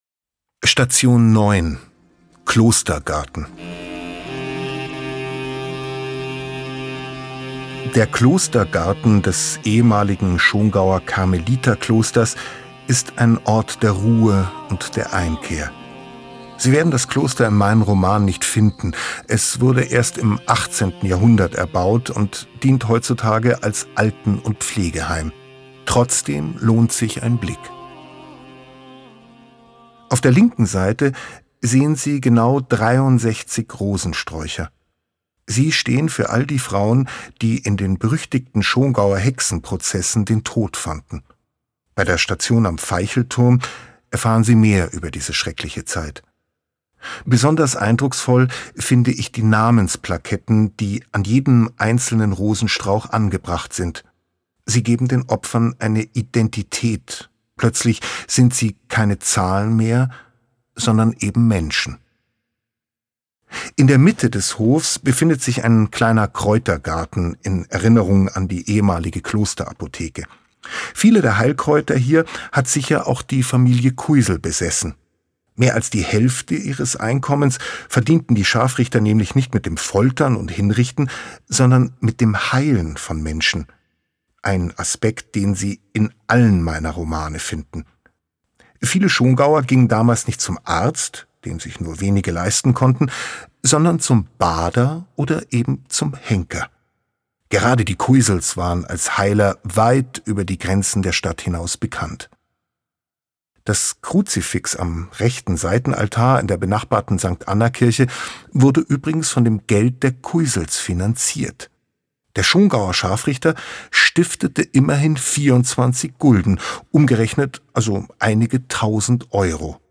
Audiokommentar Oliver Pötzsch Klosterhof
Audiokommentar zur Station 9, Klostergarten
Audioguide_Schongau-09-Klostergarten.mp3